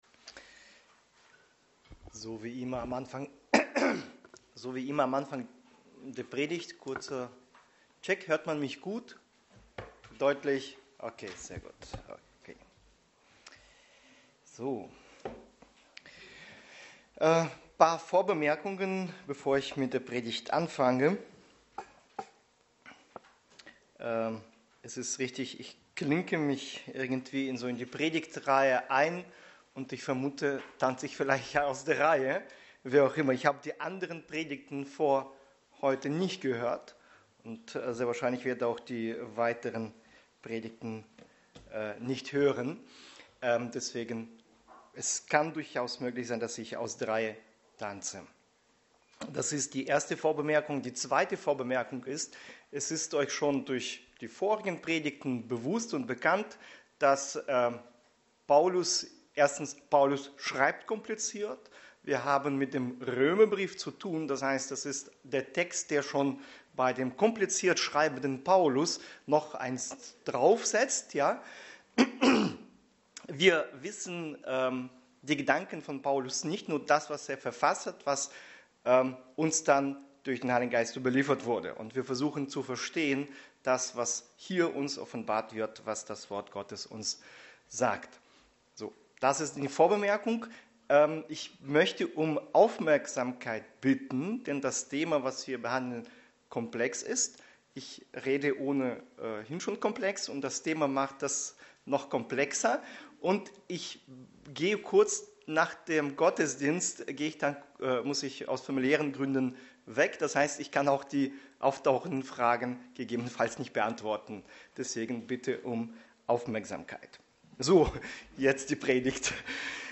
Passage: Röm 6,15-23 Dienstart: Predigt